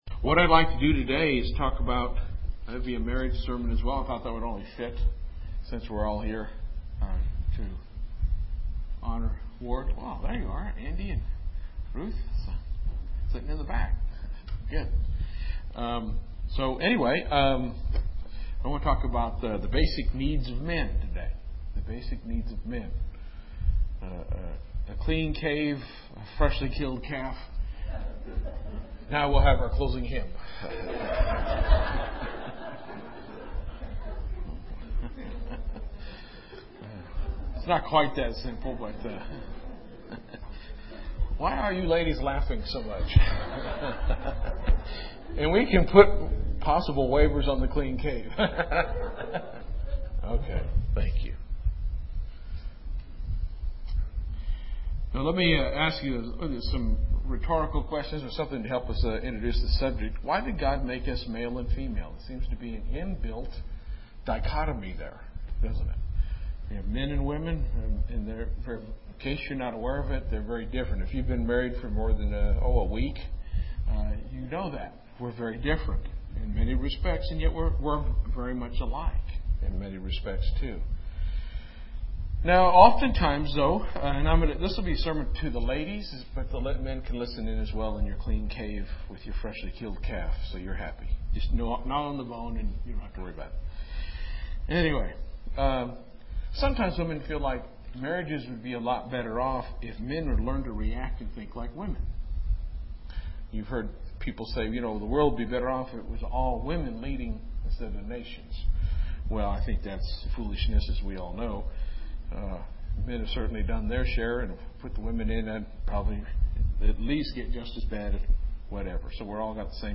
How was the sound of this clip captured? Given in Lubbock, TX